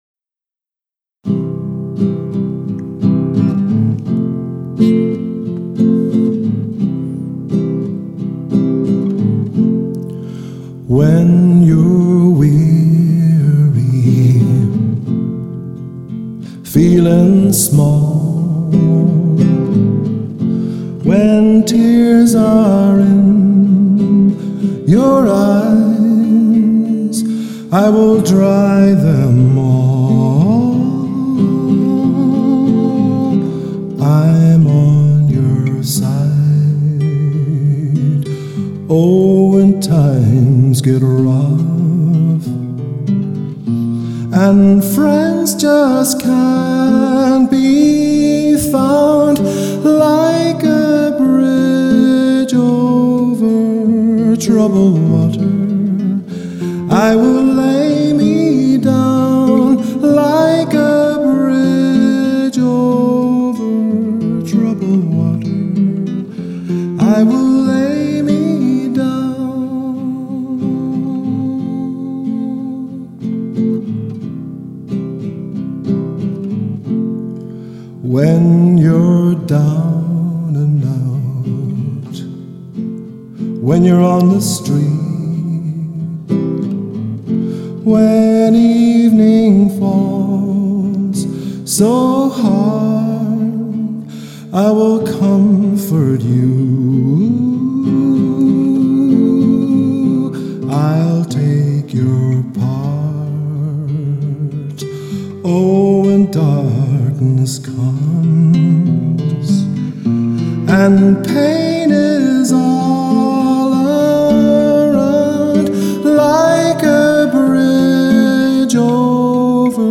voice & guitar